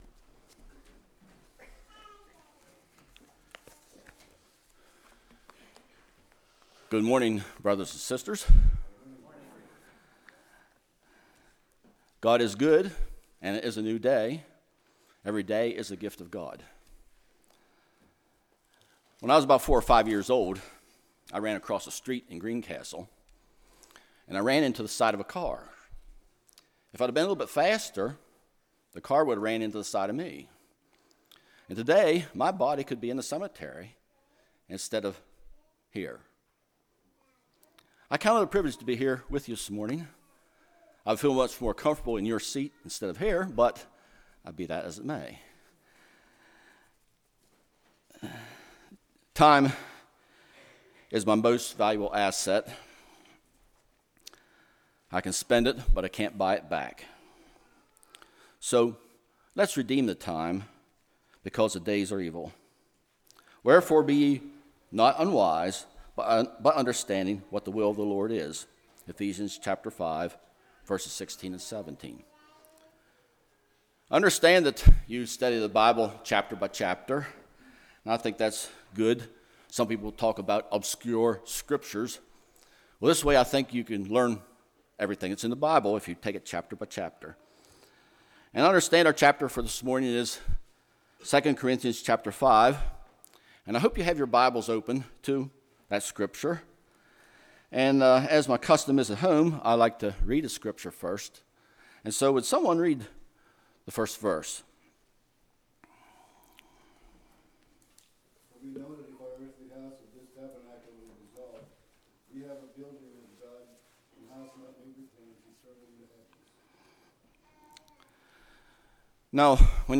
Spring Revival 2013 Passage: 2 Corinthians 5:1-21 Service Type: Sunday School